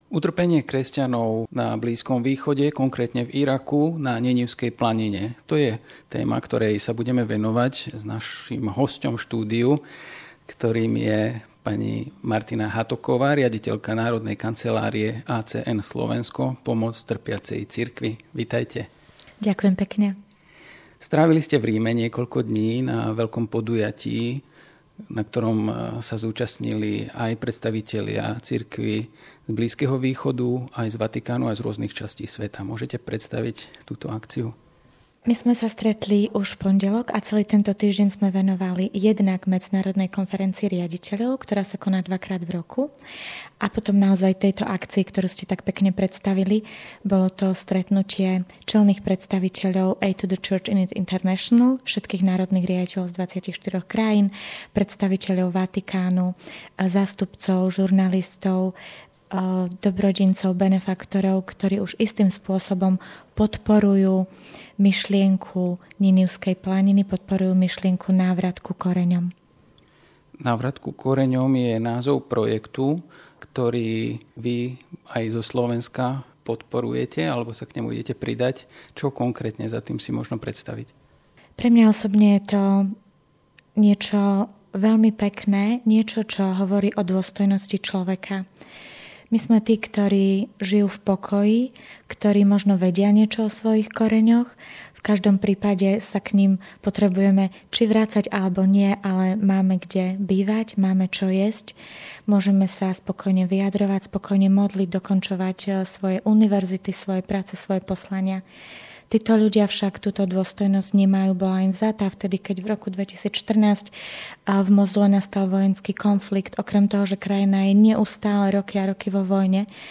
Projekt návratu kresťanov na Ninivskú planinu - rozhovor